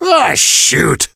gale_hurt_vo_03.ogg